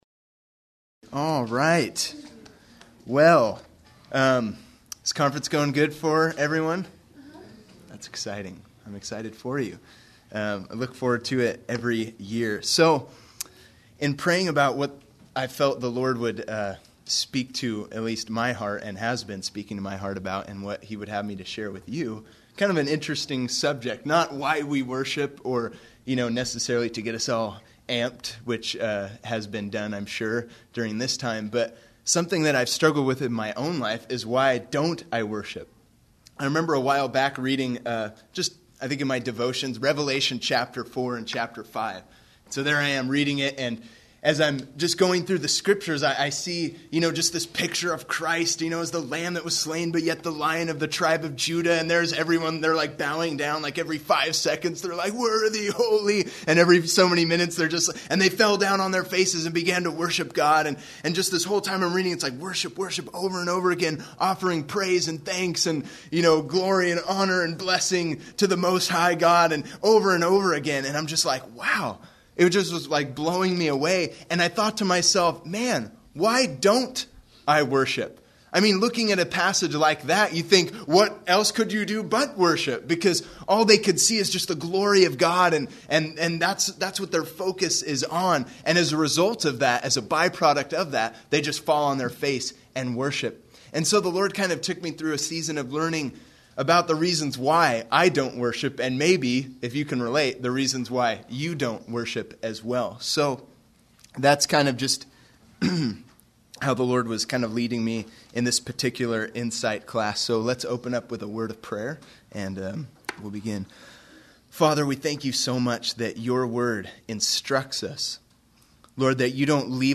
Series: 2004 Saturated Youth Worship Conference
Service Type: Workshop